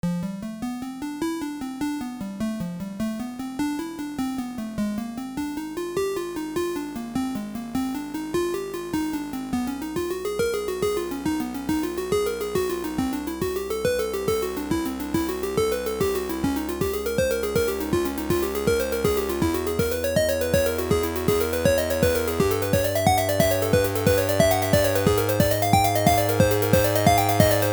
Dug through the hard drive and found this old project where I experiment with increasing the tempo.Thought it might be useful for something